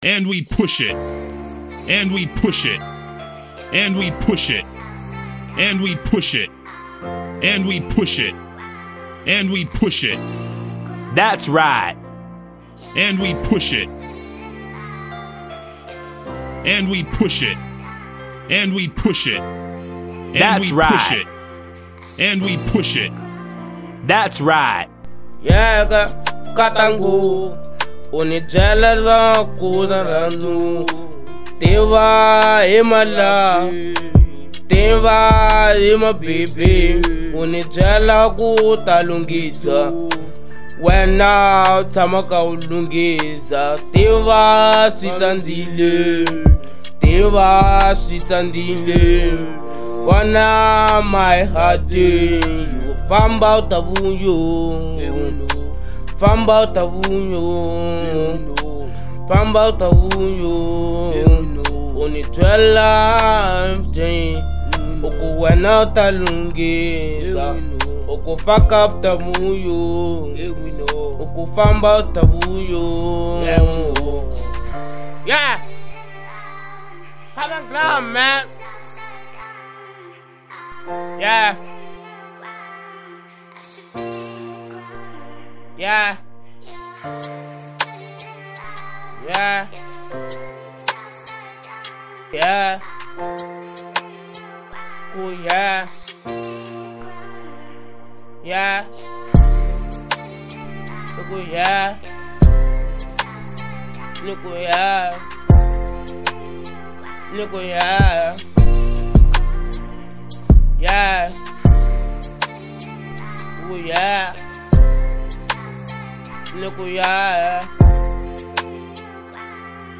02:56 Genre : Trap Size